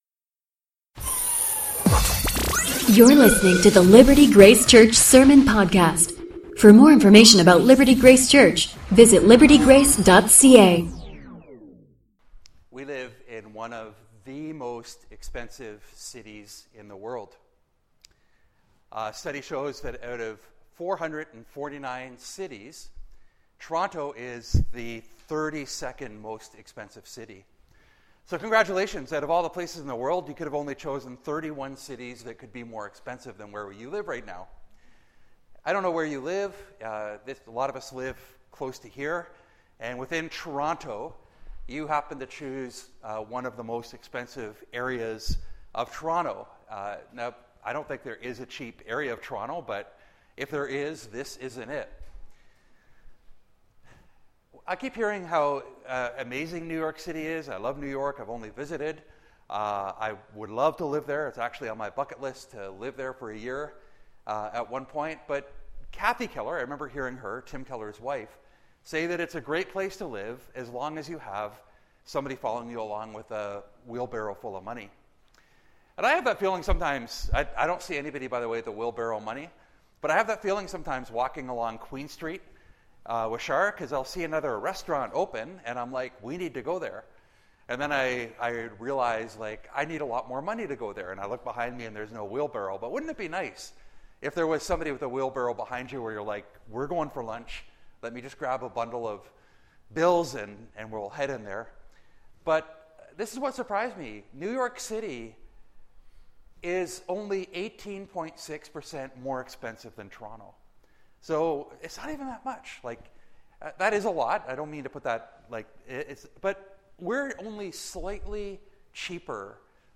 Sermons 2 Corinthians Message